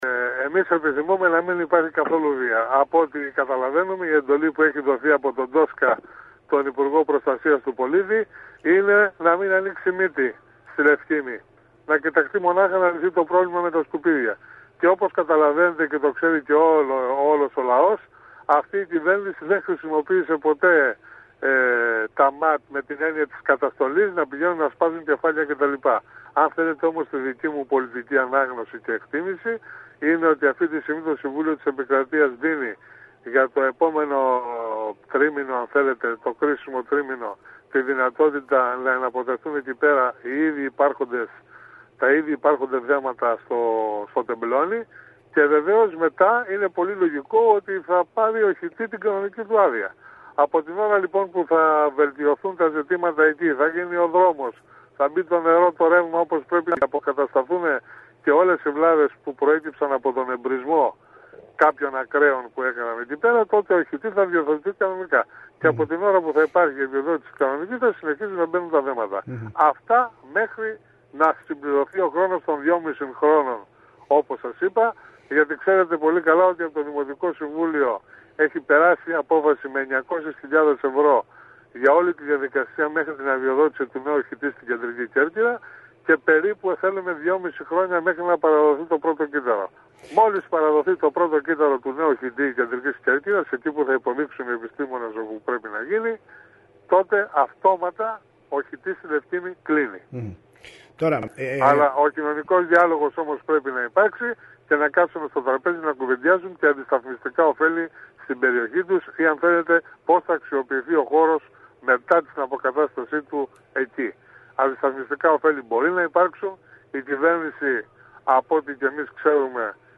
απόσπασμα των δηλώσεων